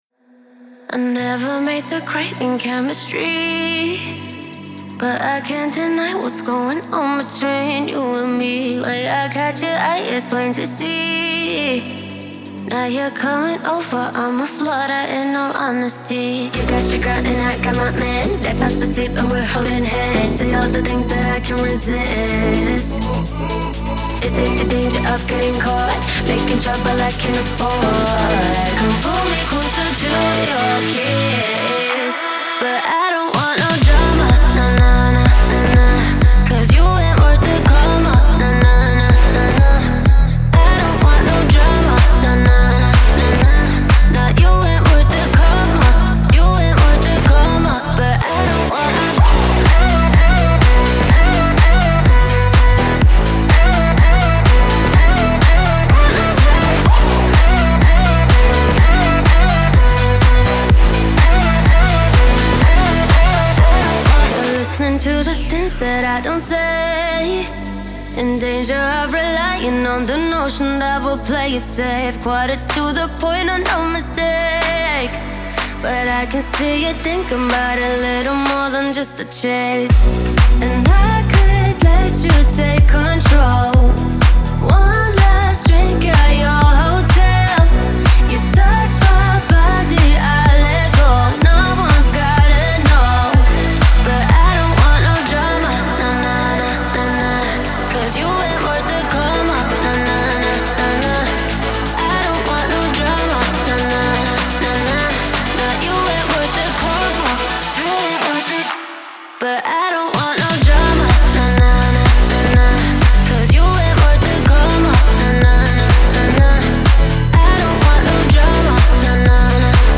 Genre: Trance, House, Hardcore, Electronic, Dance.